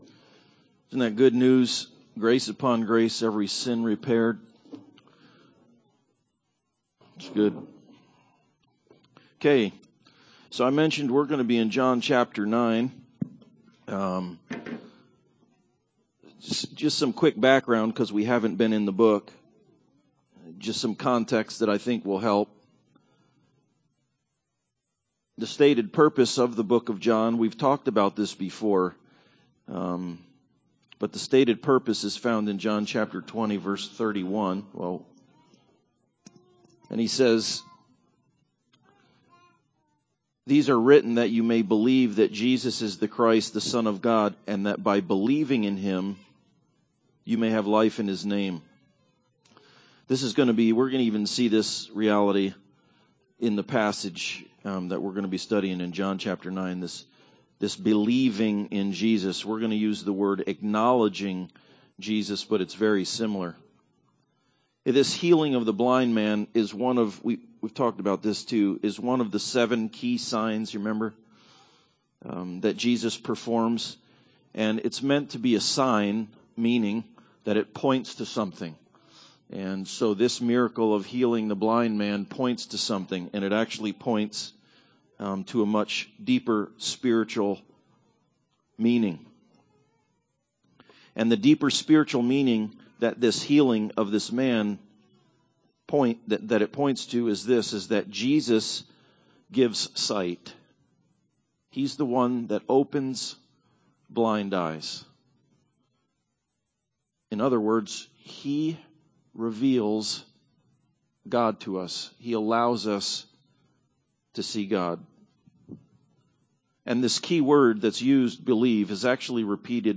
Johm 9:1-41 Service Type: Sunday Service Bible Text